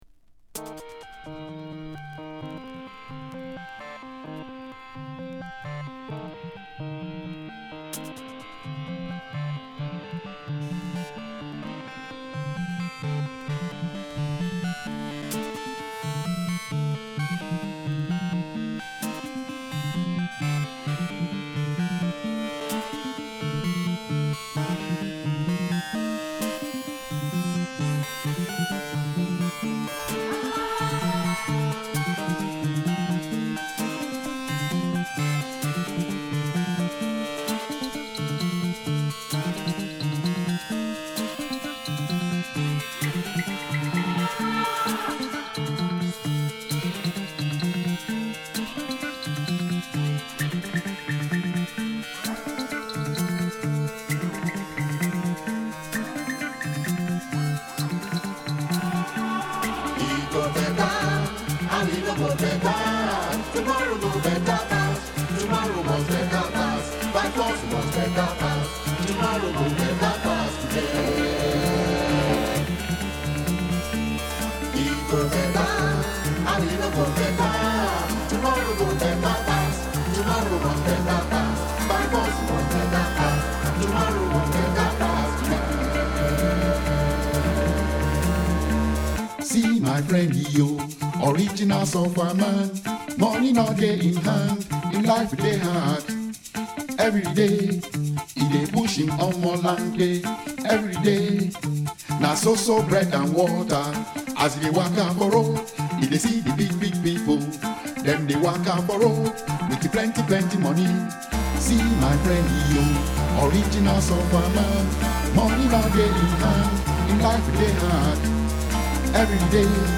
Afro House , Beatdown
Deep House